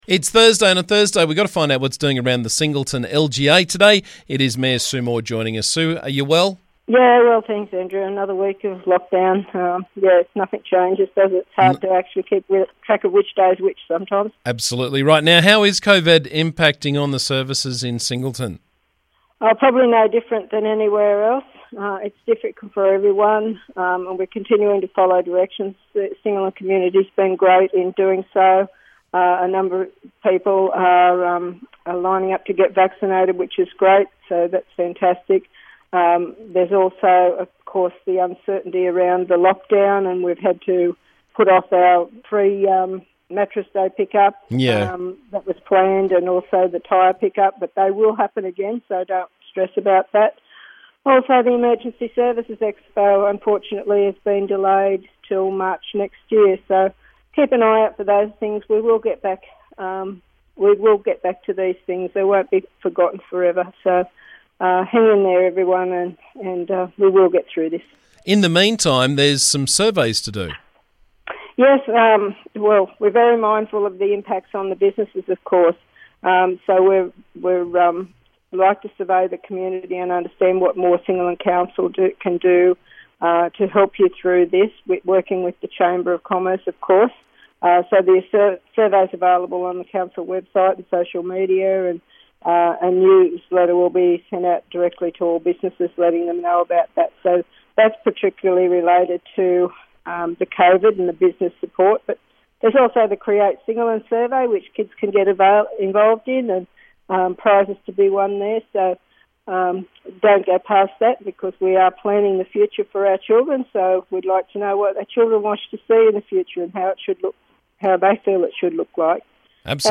Every couple of weeks we catch up with Singleton Council Mayor Sue Moore to find out what's happening around the district.